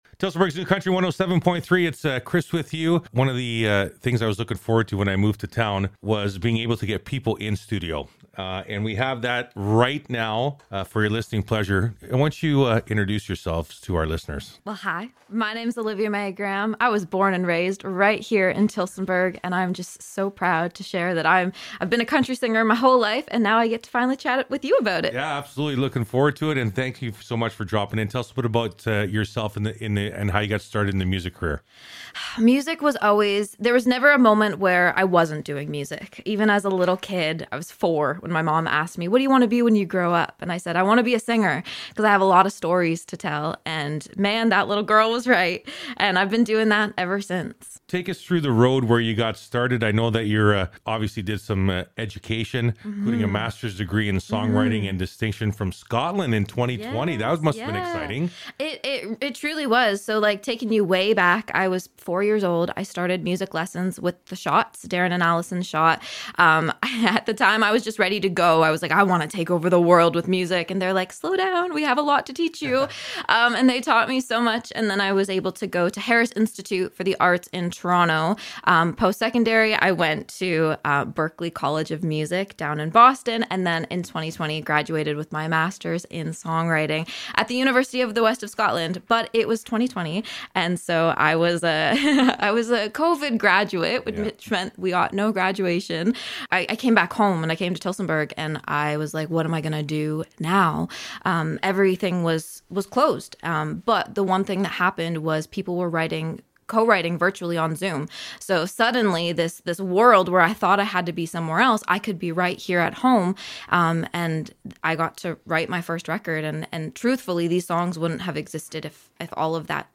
An intimate interview
we were treated to a performance in studio.